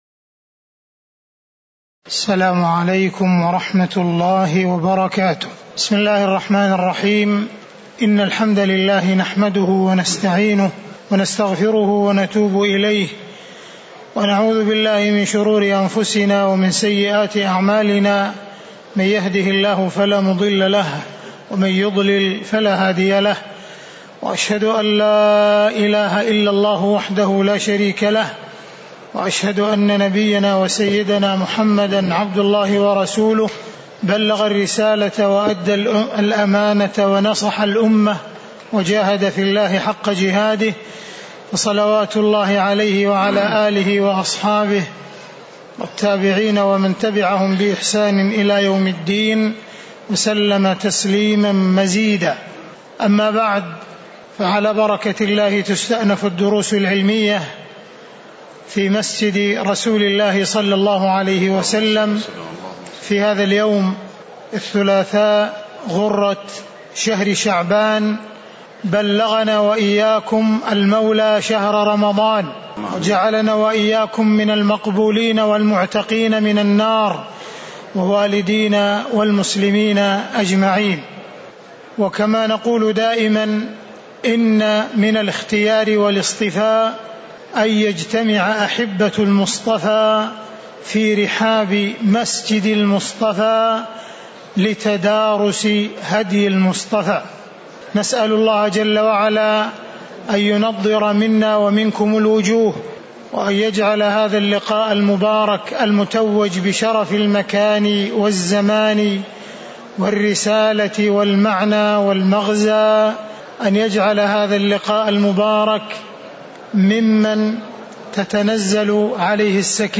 المكان: المسجد النبوي الشيخ: معالي الشيخ أ.د. عبدالرحمن بن عبدالعزيز السديس